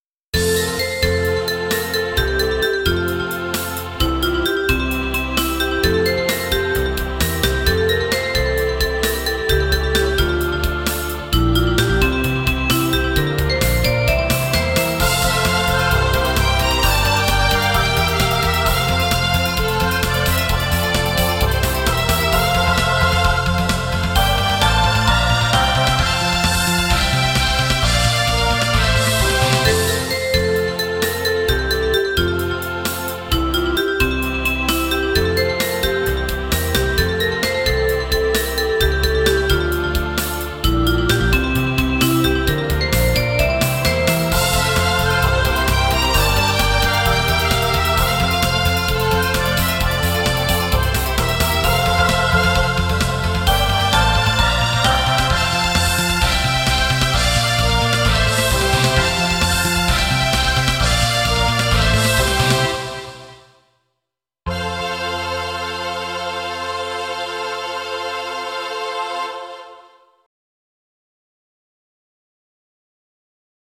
私のオリジナル曲のうち、ゲームミュージック風の曲を公開いたします。
賑やかな街の曲です。